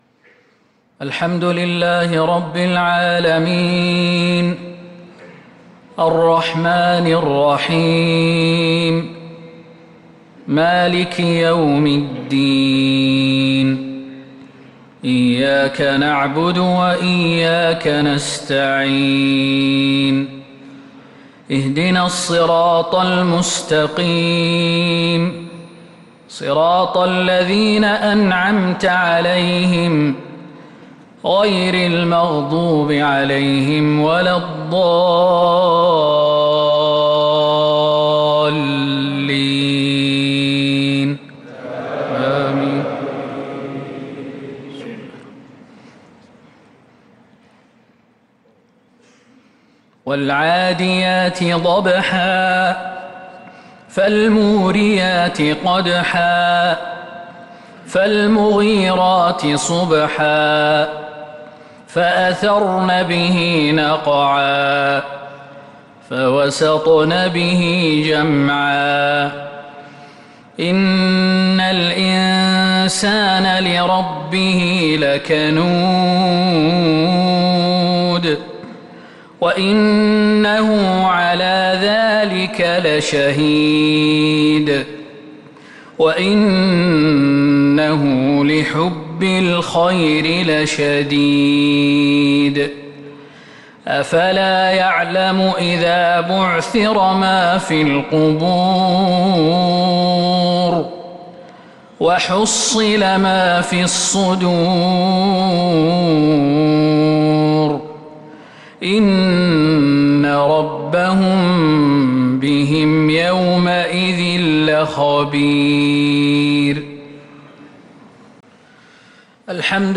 مغرب الثلاثاء 6 محرم 1447هـ سورتي العاديات و العصر كاملة | Maghrib prayer Surah Al-'Adiyat and Al-Asr 1-7-2025 > 1447 🕌 > الفروض - تلاوات الحرمين